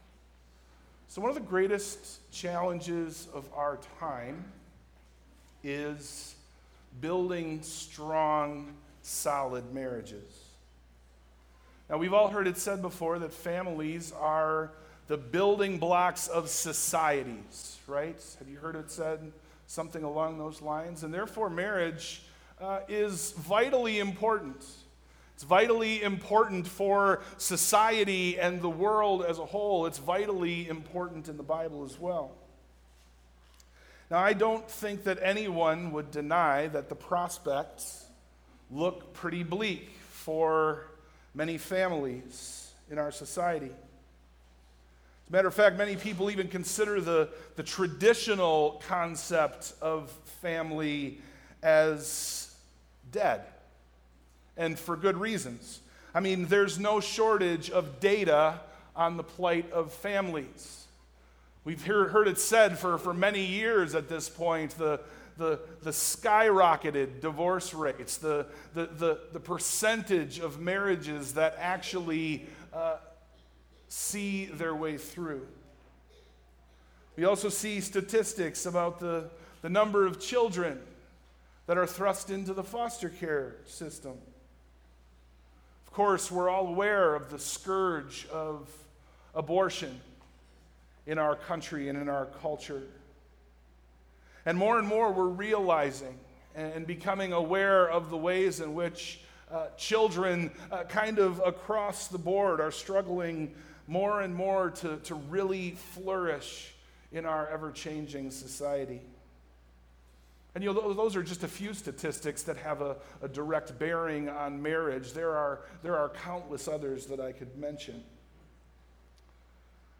Sermon+Audio+-+Wisdom+in+Marriage.mp3